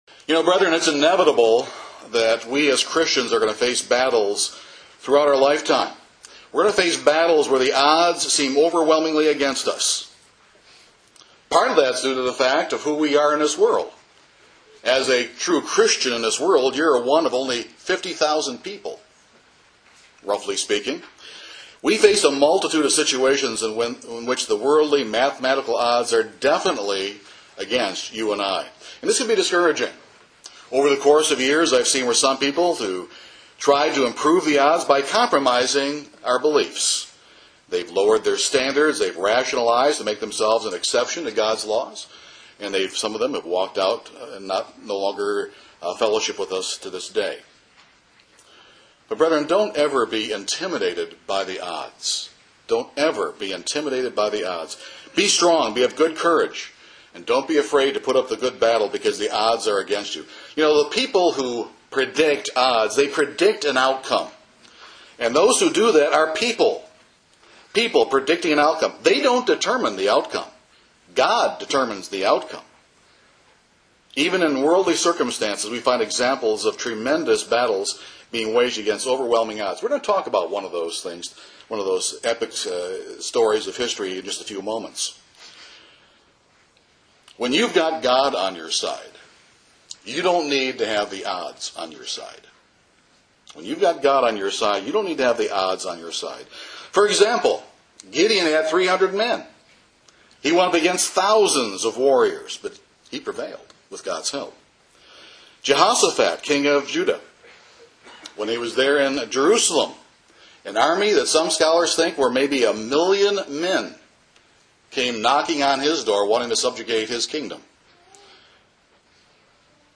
This sermon shows the inspiring parallels when God miraculously intervened to save His people at the Red Sea and at Dunkirk. These miracles stand as testimony to God’s desire to stand miraculously with you in your troubles.